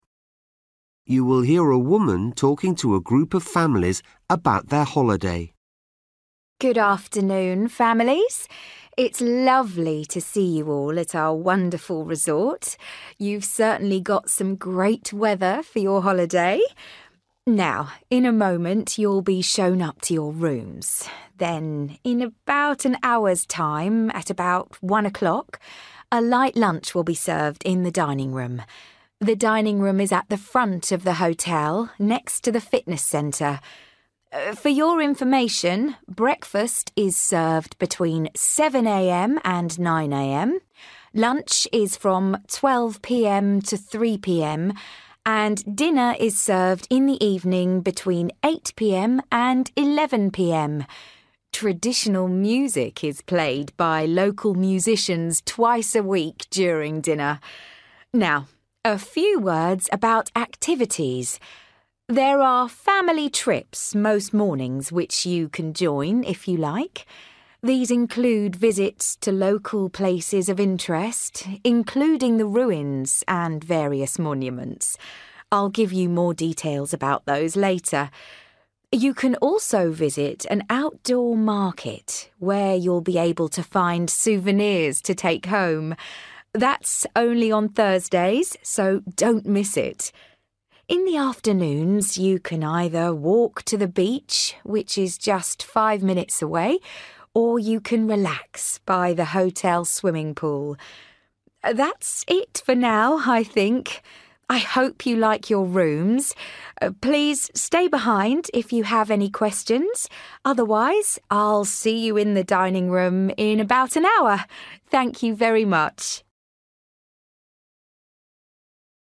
You will hear a woman talking to a group of families about their holiday.